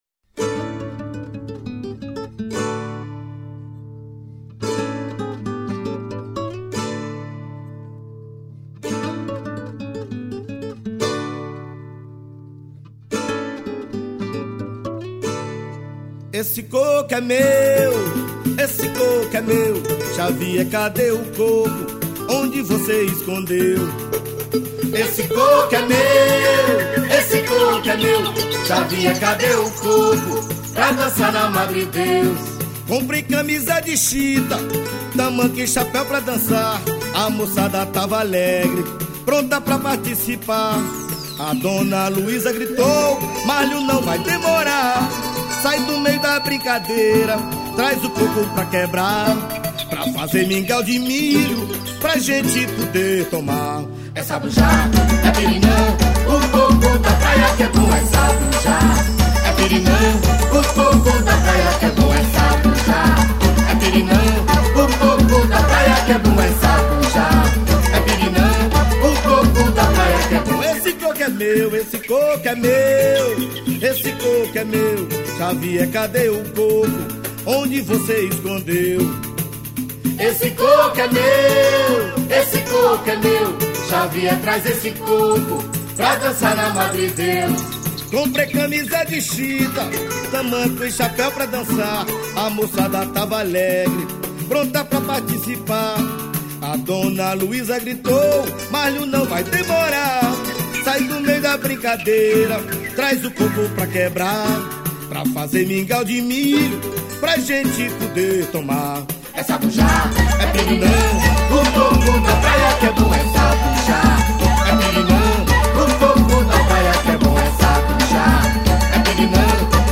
1220   03:21:00   Faixa:     Côco